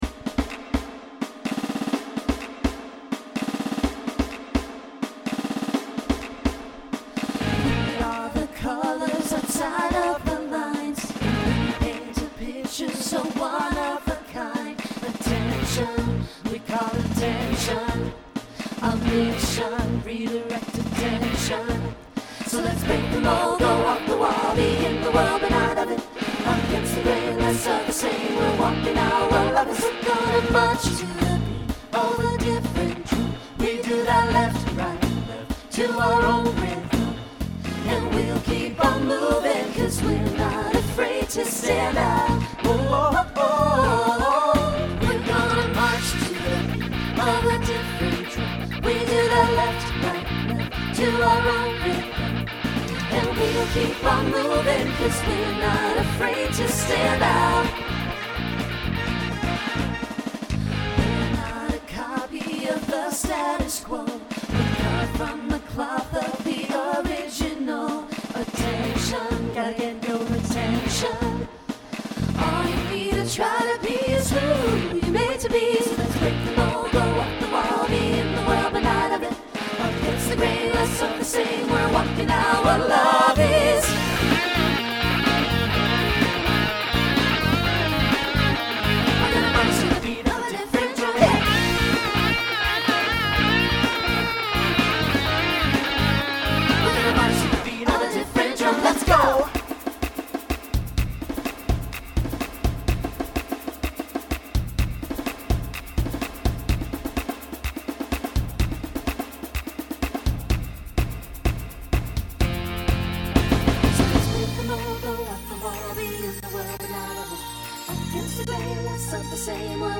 New TTB voicing for 2025.